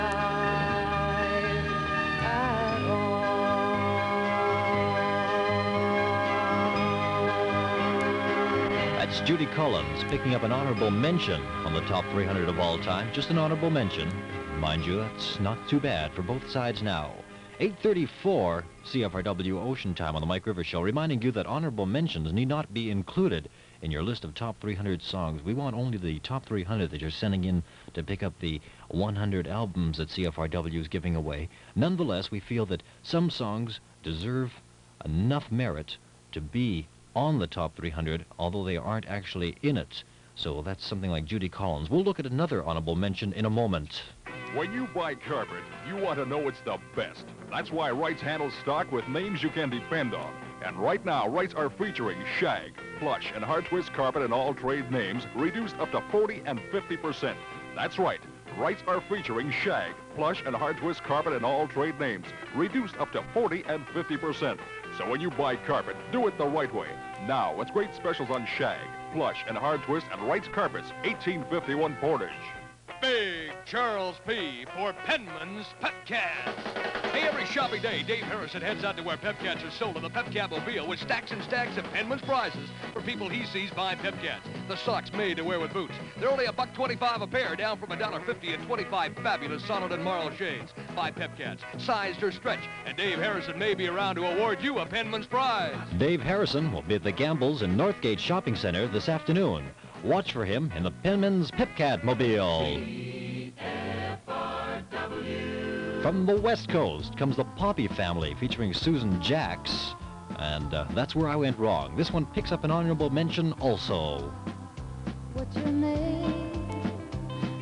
Winnipeg Radio in 1971